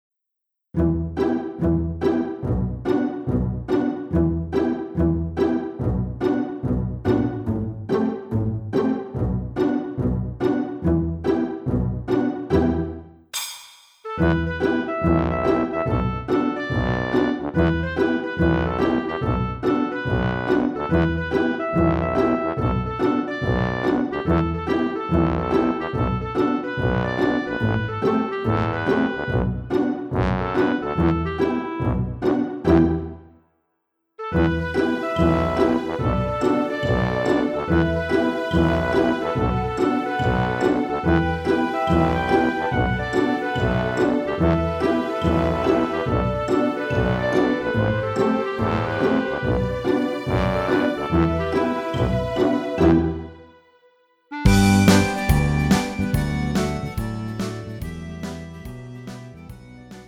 음정 -1키 3:13
장르 pop 구분 Pro MR